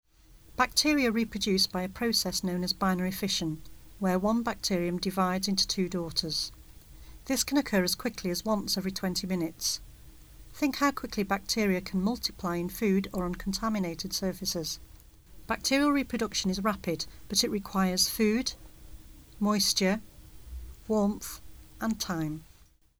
Narration audio (MP3) Narration audio (OGG) Select the clock below to see how rapdily one bacterium could multiply.